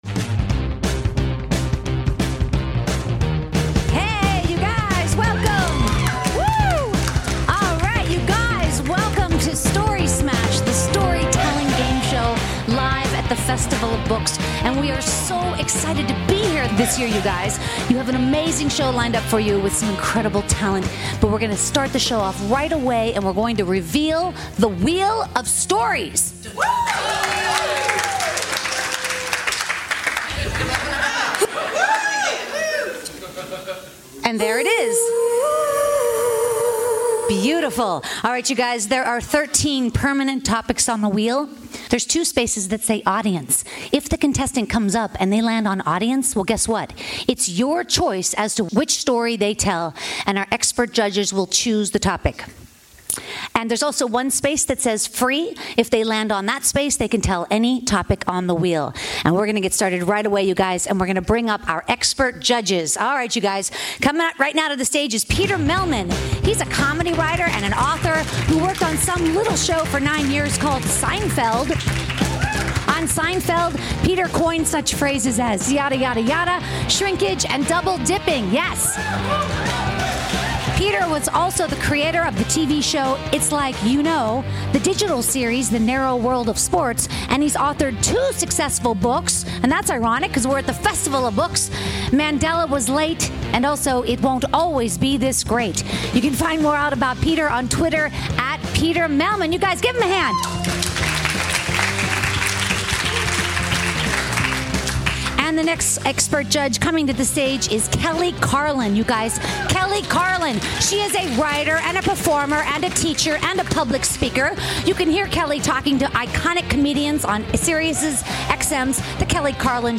Story Smash The Storytelling Gameshow LIVE at The Los Angeles Times Festival of Books